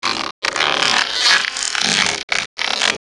ALIEN_Communication_33_mono.wav